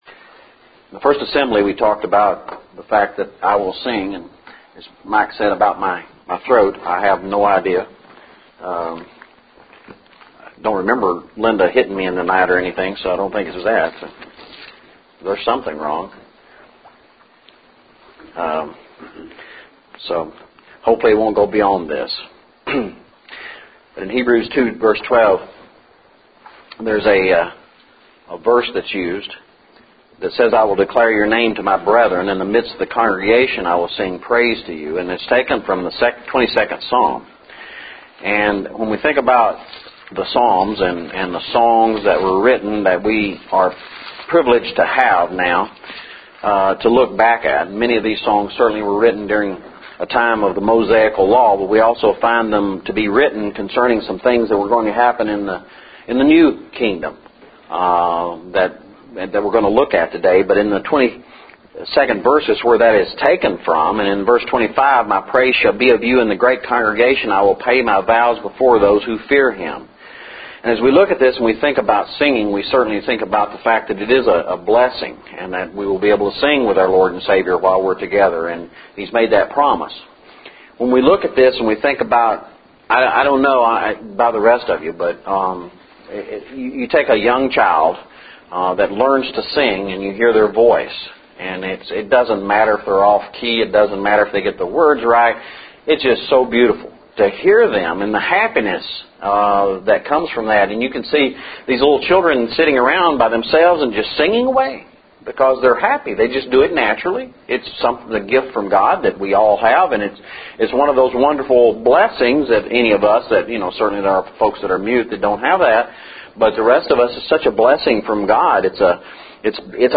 Messiah Prophecy Lesson – 10/30/11